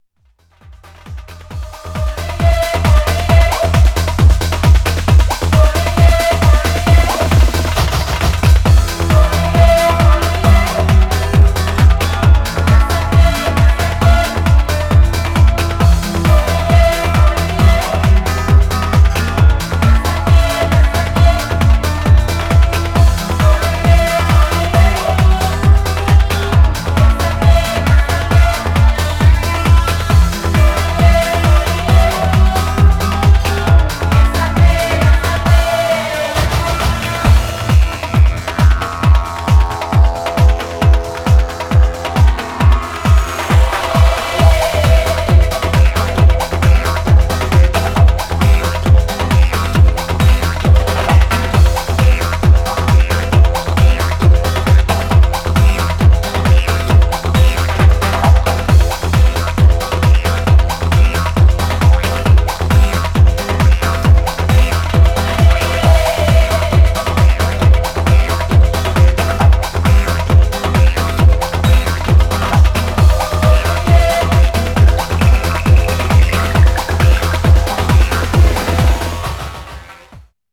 Styl: House, Techno
B2. Club Mix